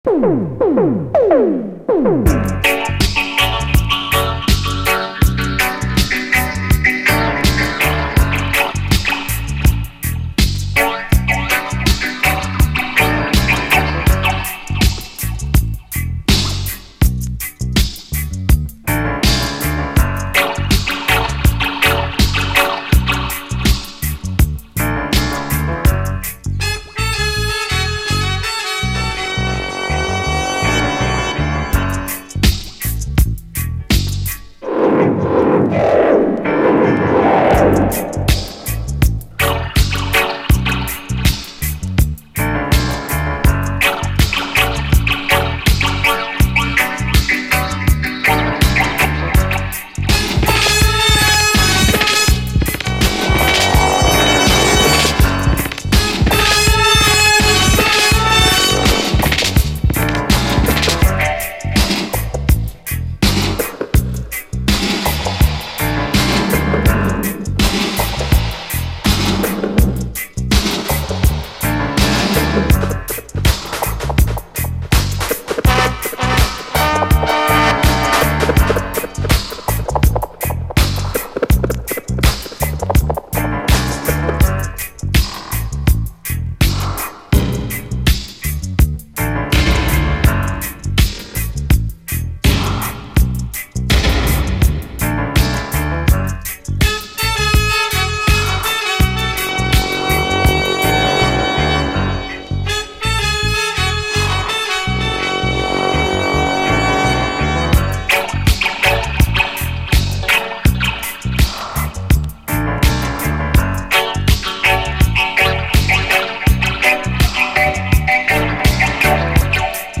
REGGAE
都市ゲリラ型硬質ダブ・ワークが強烈にヤバい
多彩に次々と繰り出される都市ゲリラ型の硬質ダブ・ワークは、今もって激フレッシュ！